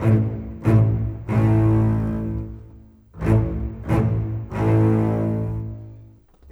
Rock-Pop 06 Bass _ Cello 02.wav